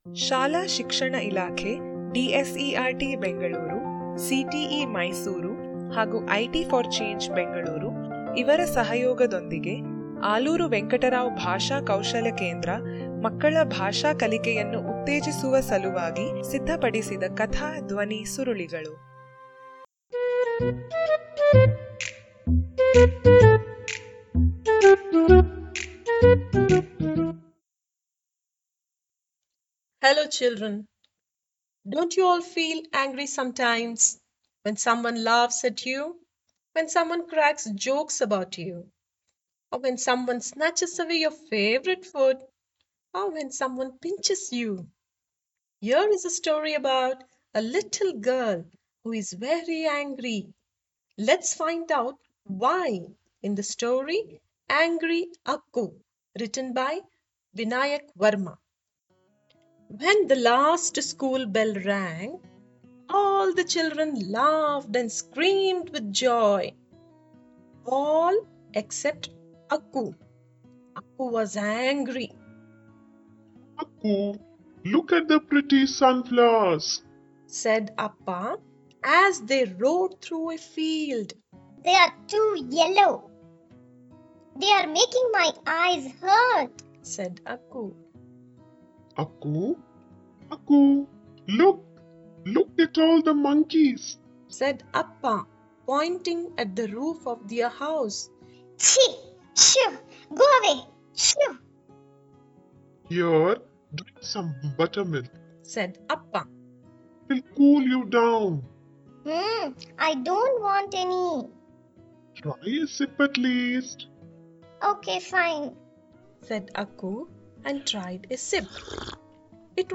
Angry Akku - Audio Story Activity Page